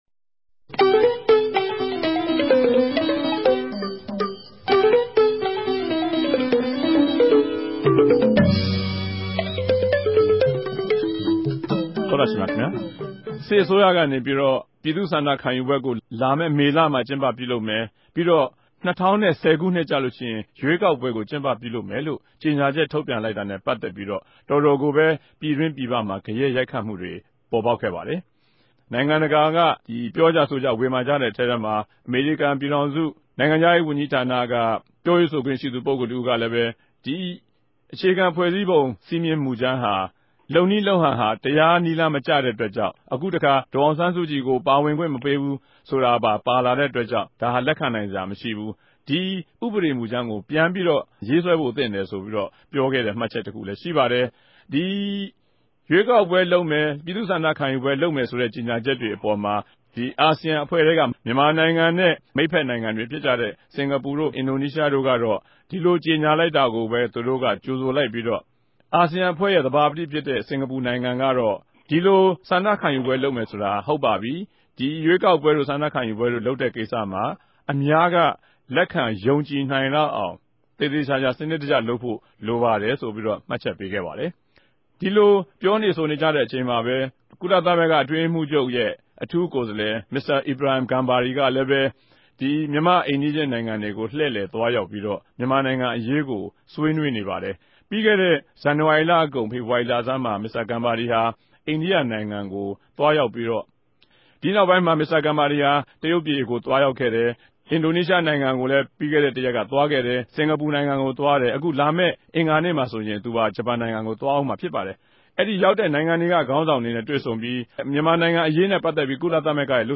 တပတ်အတြင်း သတင်းသုံးသပ်ခဵက် စကားဝိုင်း